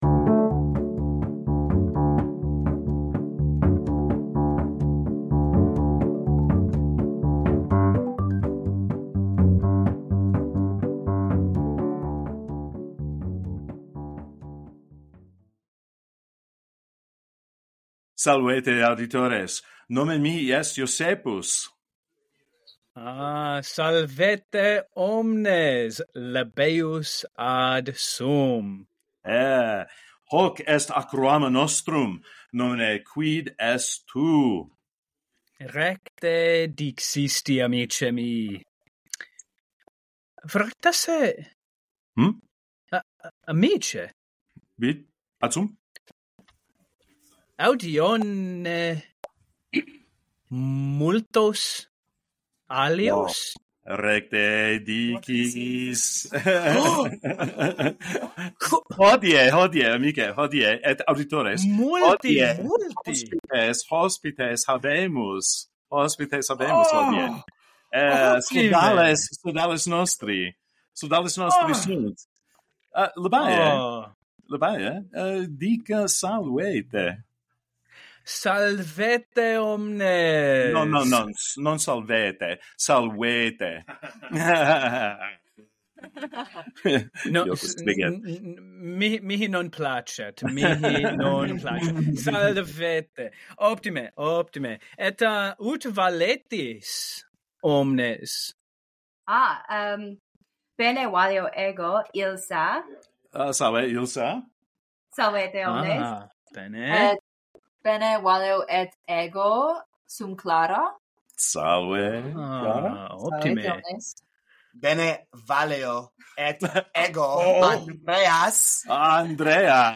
One takes on a part and the other tries to the guess the word. This podcast aims to present novice level content in Latin. Twenty Questions meets Guess Who!A special thanks to our friends at SALVI for joining us on this episode!